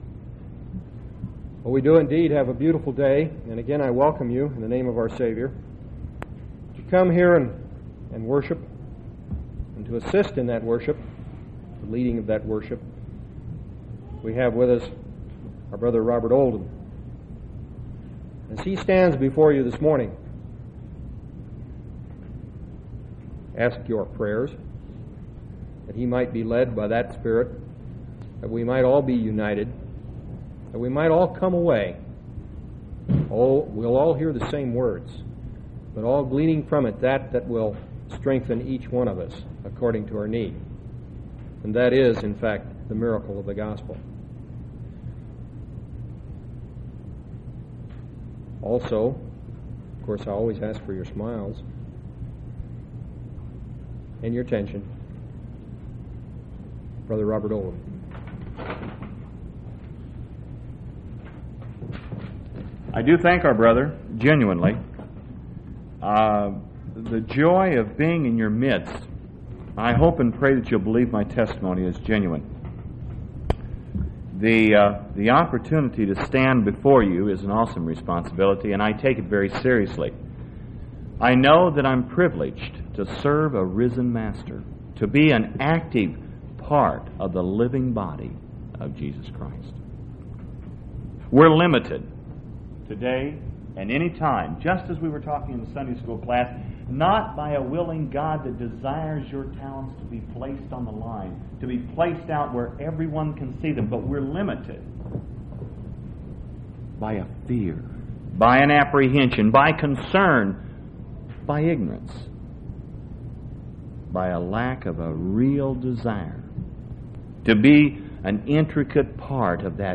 6/29/1986 Location: East Independence Local Event